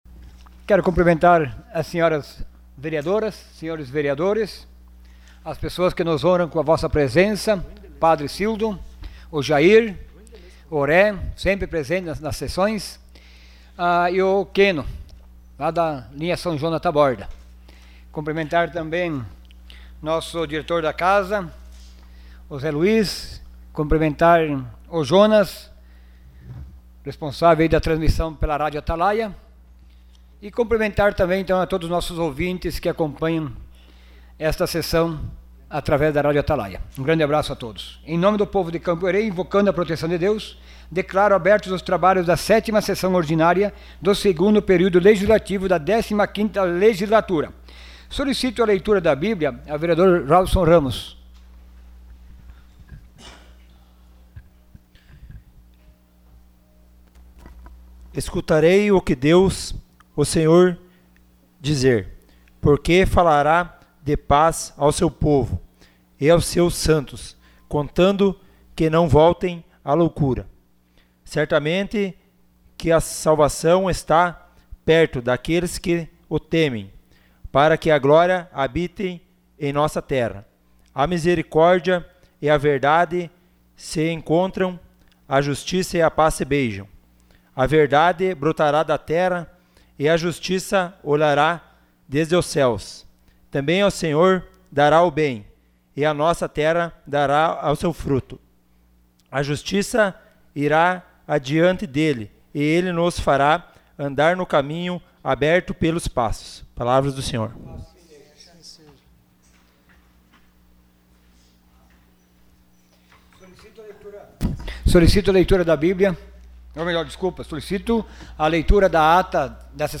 Sessão Ordinária dia 05 de março de 2018.